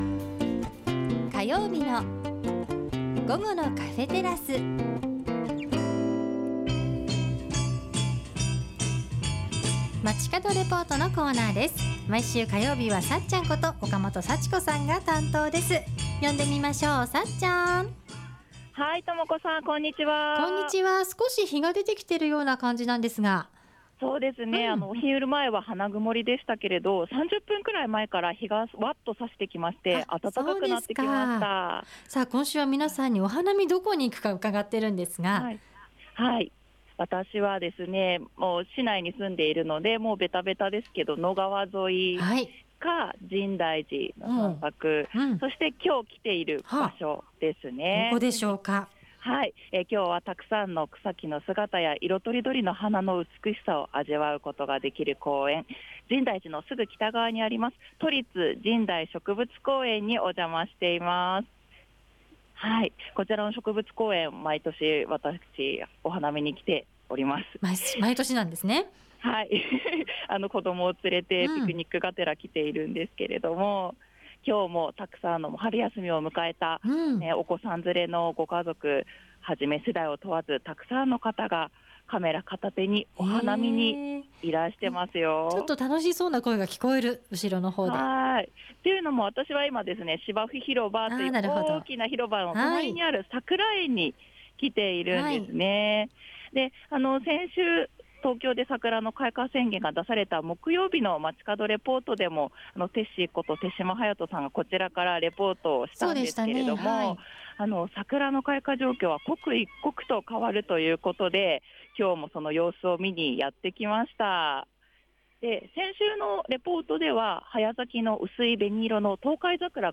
午後のカフェテラス 街角レポート
お昼前までは花曇りでしたが、中継前に日が差して青空が広がり始めてきましたよ♪ 今日は今週のメッセージテーマ「お花見はどこに行く？」にぴったりの場所、 深大寺のすぐ北側にある「都立神代植物公園」の「さくら園」から中継しました♪